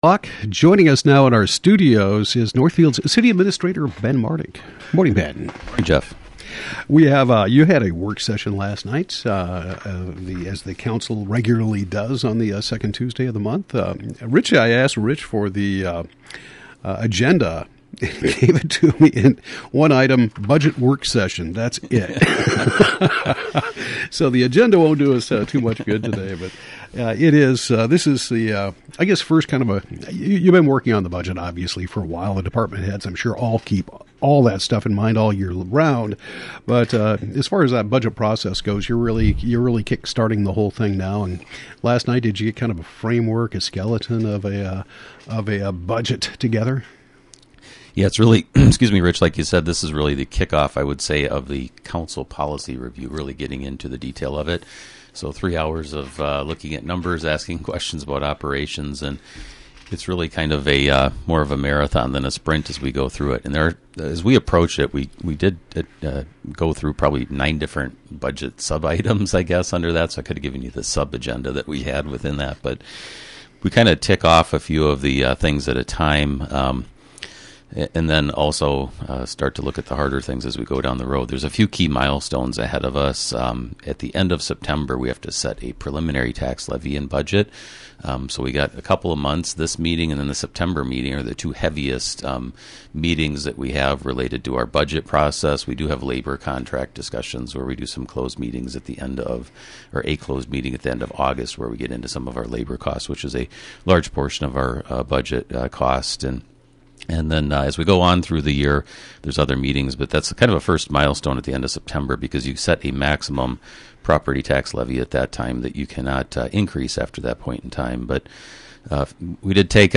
Northfield City Administrator Ben Martig talks about the Northfield City Council Budget Work Session held on August 10, 2021.